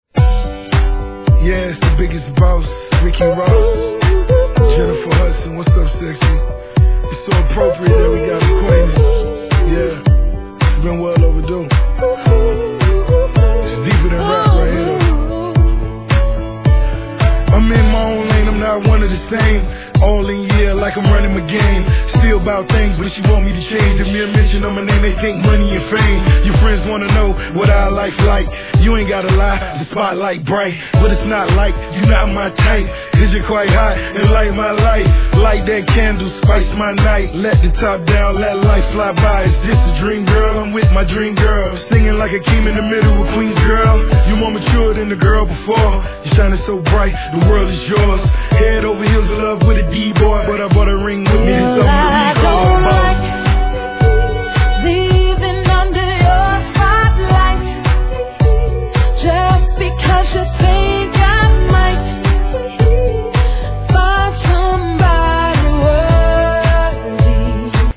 HIP HOP/R&B
哀愁のダンスナンバーです!